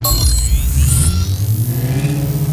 gauss_precision_start.wav